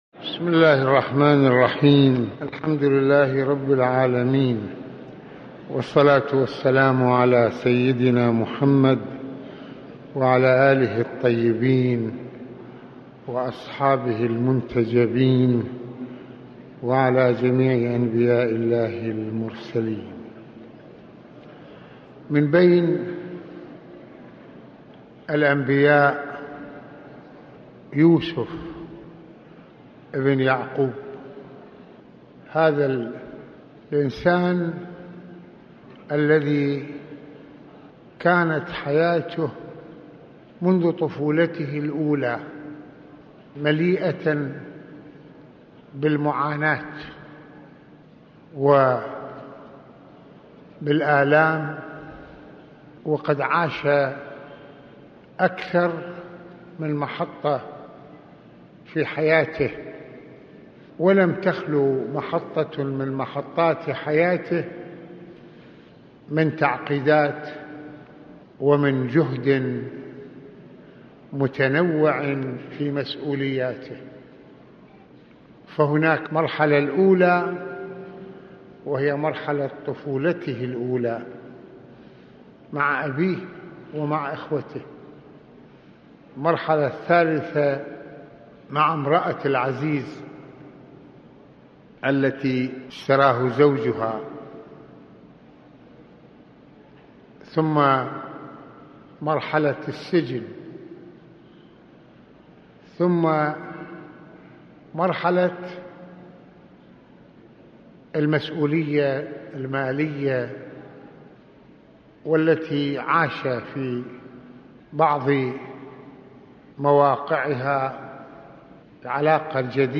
- يتحدث سماحة المرجع السيد محمد حسين فضل الله(رض) في هذه المحاضرة عن النبي يوسف (ع) وأبرز محطات عمره الشريف المليئة بالمعاناة والمسؤوليات ، وما يمكن أن نستفيد منها في إصلاح أمورنا، ويتناول سماحته سورة يوسف بالشرح من حيث المضمونات والأبعاد، وما شكّلته هذه القصة من عِبَر للأمم في تجلياتها ومعانيها ومفاهيمها ...